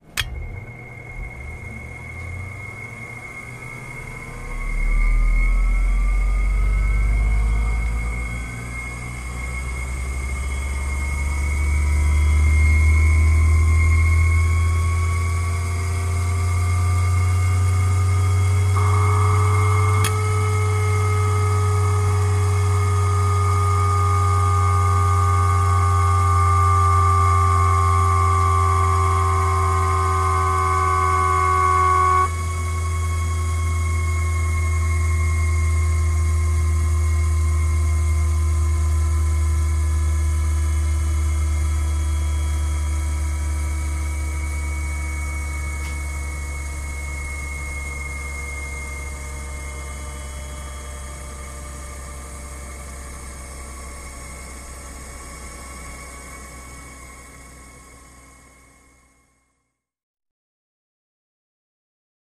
Machine, Wind Up / Down; Large Centrifuge Clicks On, Winds Up And Down. Left Channel Air Mic, Right Channel Contact Mic